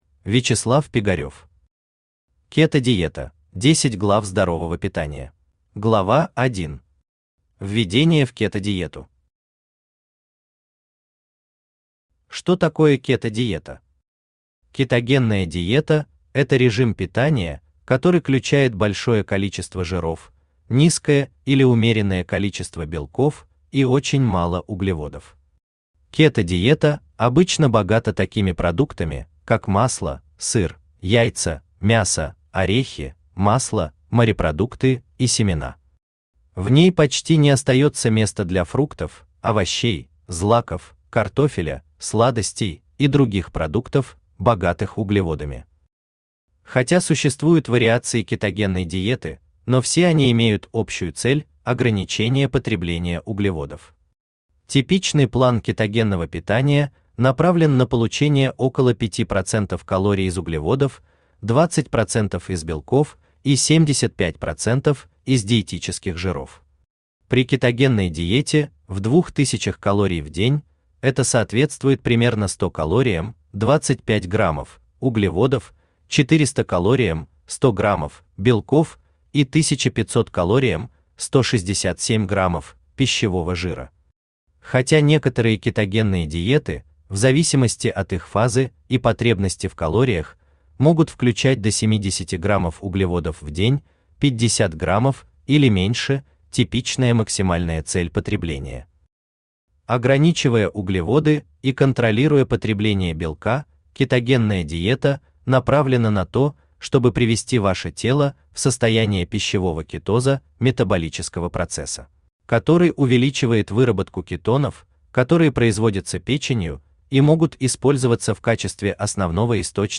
Аудиокнига Кето-диета: 10 глав здорового питания | Библиотека аудиокниг
Aудиокнига Кето-диета: 10 глав здорового питания Автор Вячеслав Пигарев Читает аудиокнигу Авточтец ЛитРес.